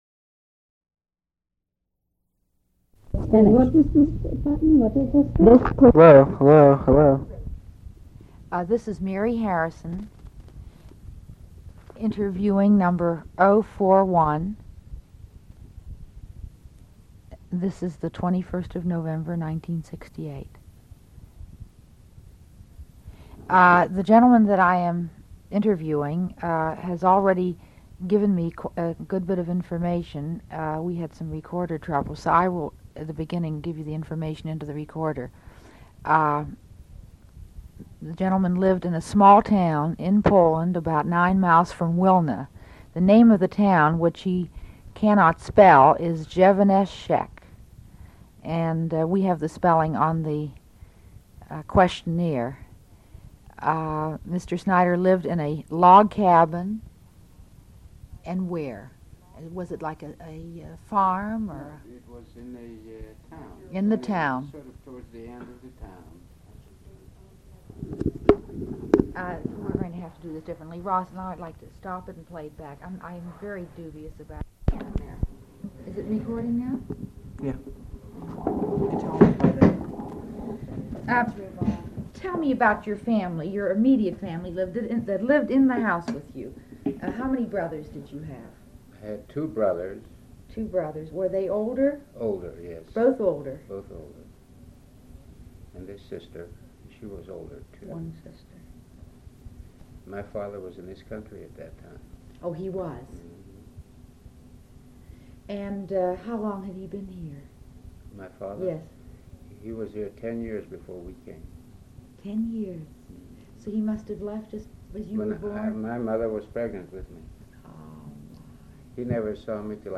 Interviewer provides initial biographical details. Father's previous immigration to U.S. is noted.